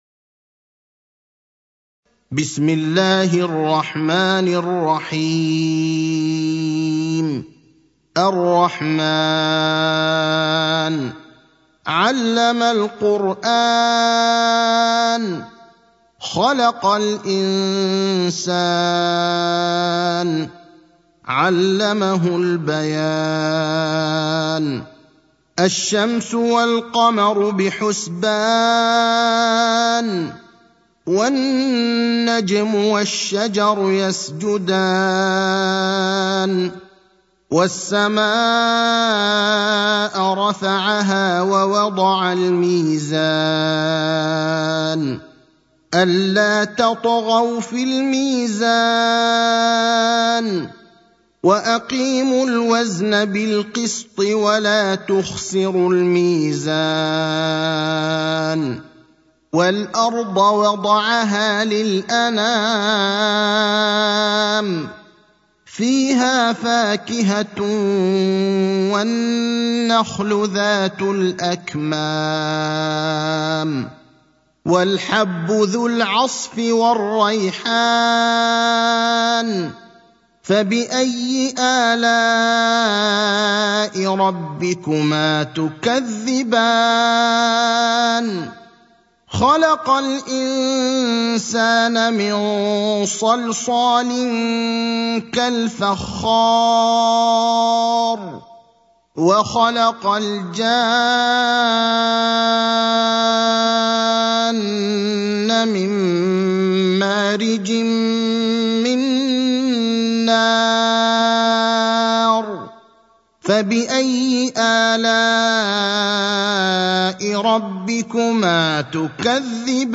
المكان: المسجد النبوي الشيخ: فضيلة الشيخ إبراهيم الأخضر فضيلة الشيخ إبراهيم الأخضر سورة الرحمن